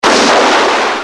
جلوه های صوتی
دانلود صدای بمب و موشک 33 از ساعد نیوز با لینک مستقیم و کیفیت بالا